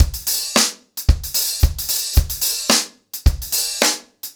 DaveAndMe-110BPM.3.wav